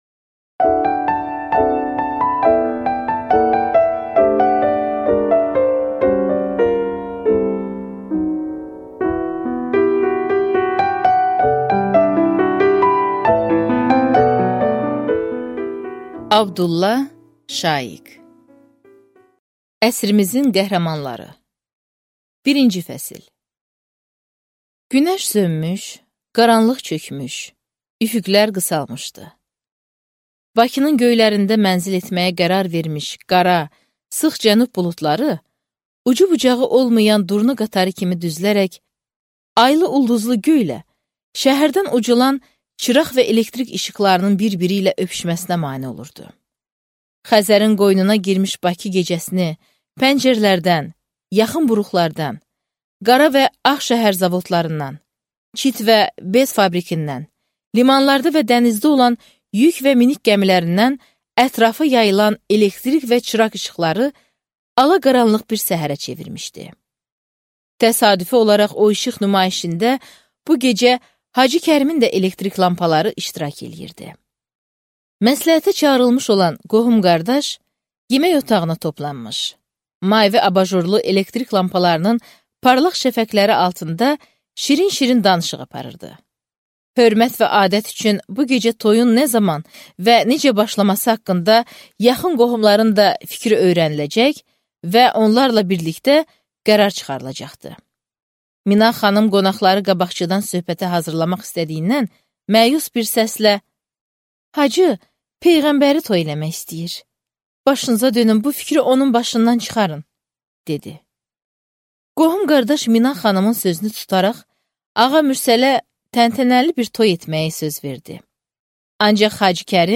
Аудиокнига Əsrimizin qəhrəmanları | Библиотека аудиокниг